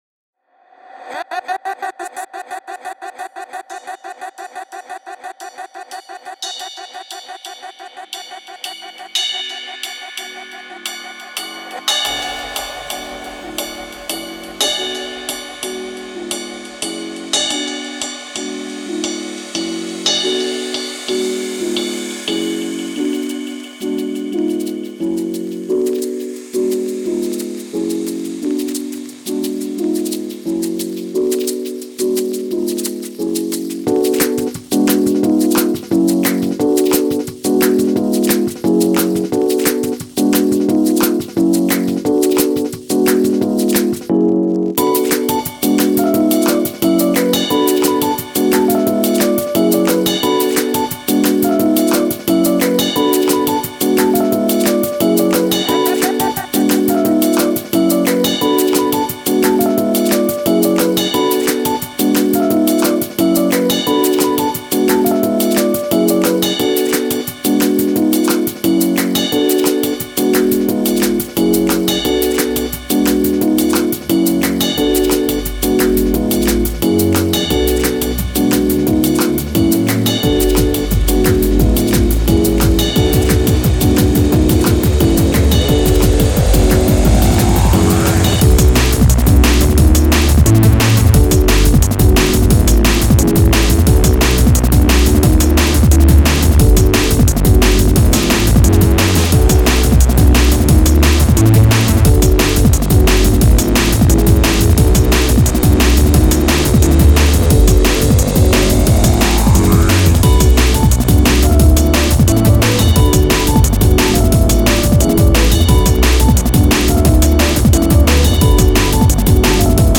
pretty standard liquid funk track